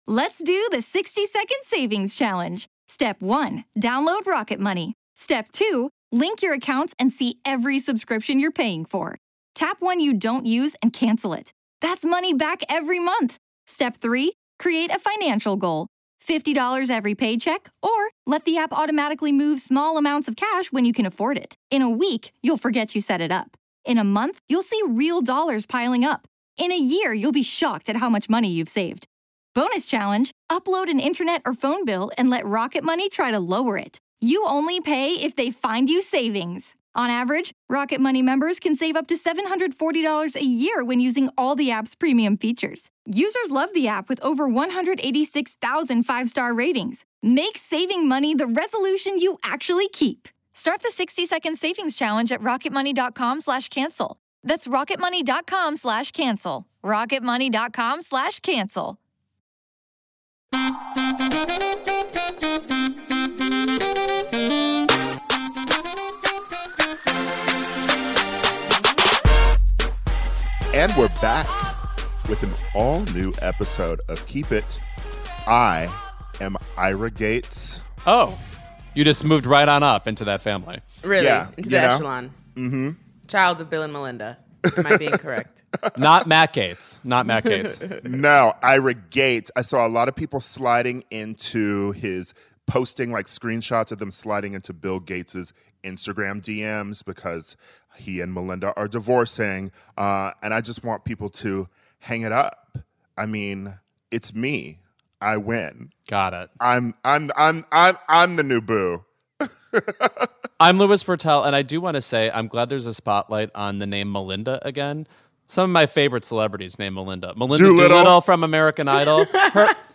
Keep It listeners call in for dating advice